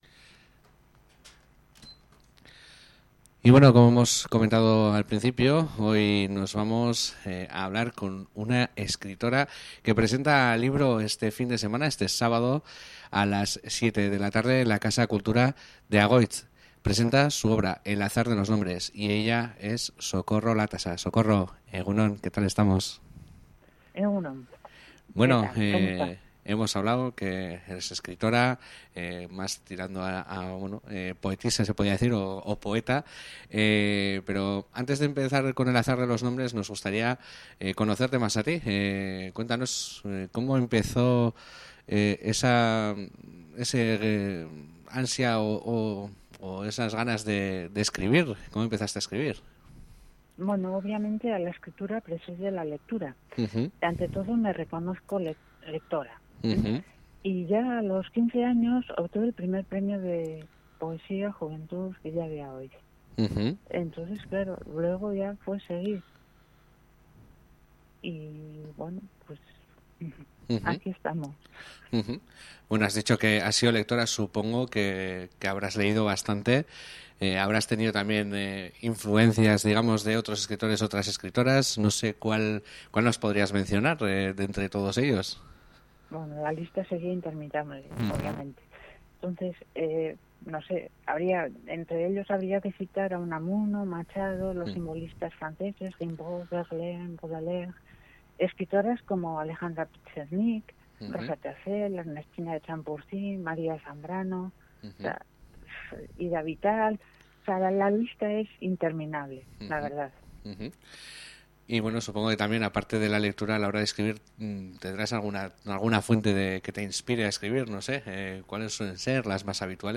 Gure gozamenerako ere hainbat poema utzi dizkigu.